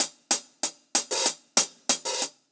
Index of /musicradar/ultimate-hihat-samples/95bpm
UHH_AcoustiHatB_95-04.wav